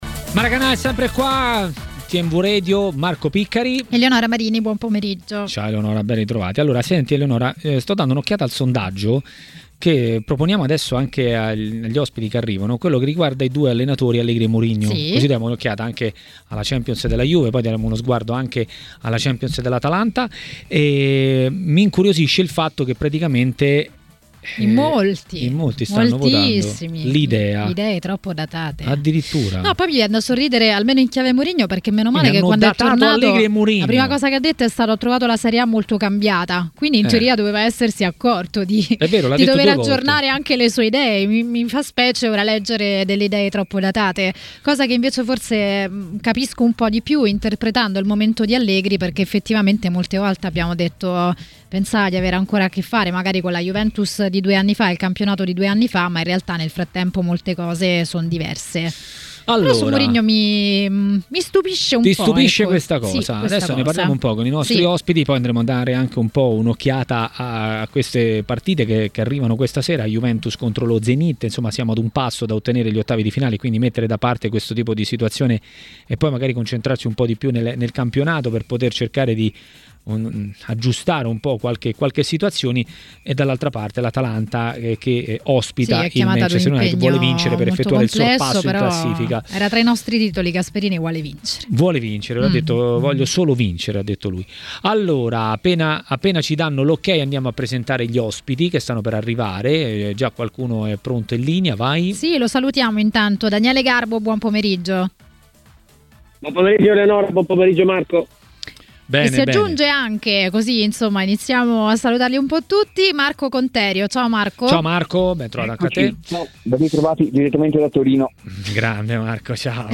A commentare il momento della Juventus e non solo a Maracanà, nel pomeriggio di TMW Radio, è stato l'ex difensore Fabio Petruzzi.